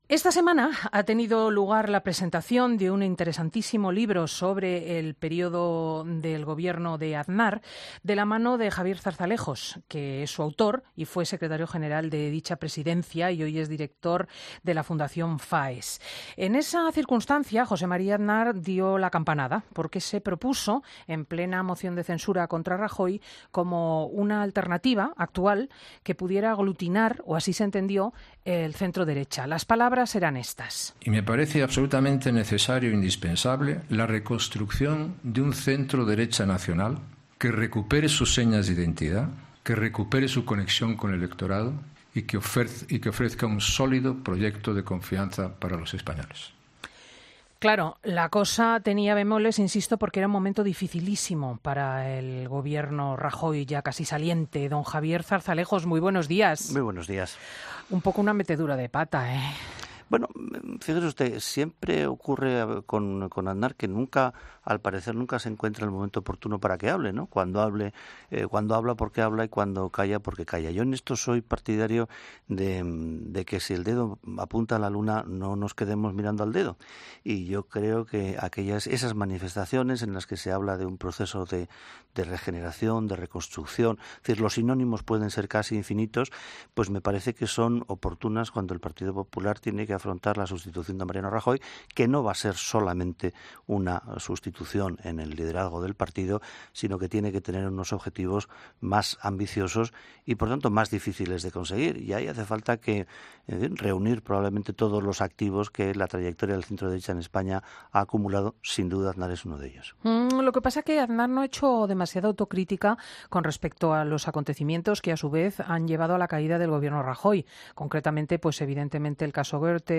Escucha la entrevista a Javier Zarzalejos en 'Fin de Semana'